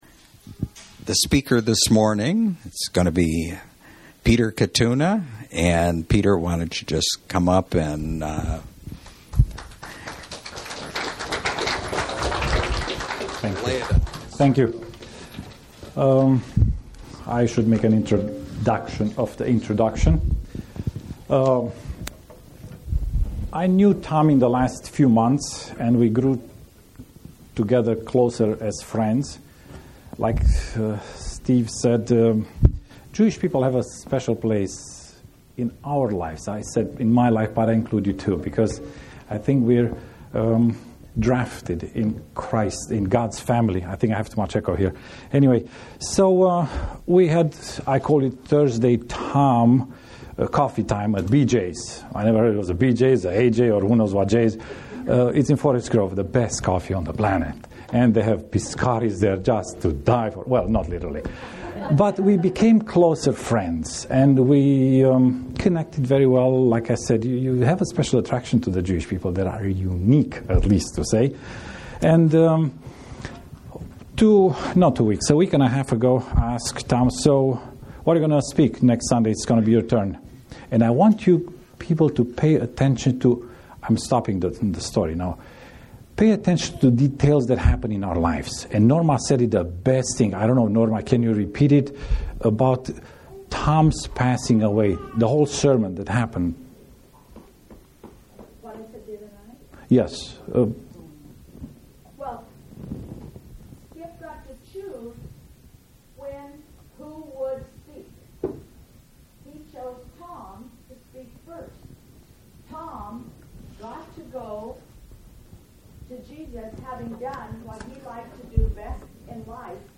Sunday Morning Message for May 10, 2015